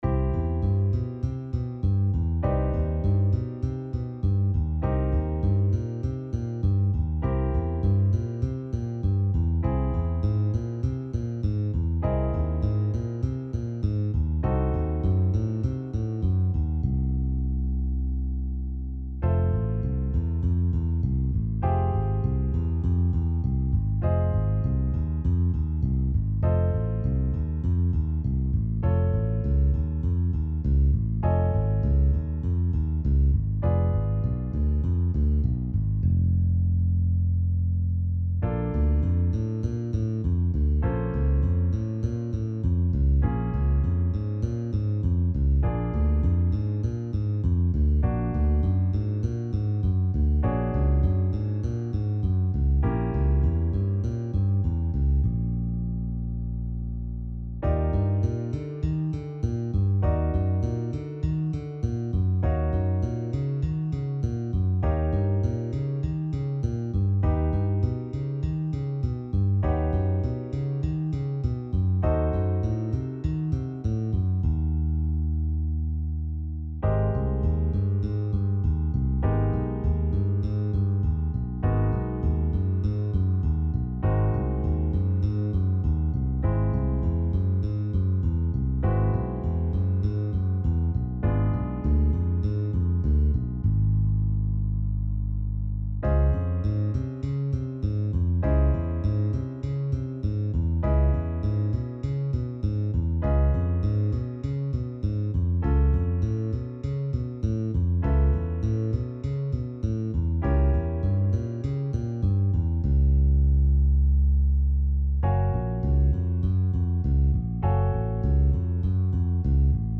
chord-tone-ex.-flow-1-bass.mp3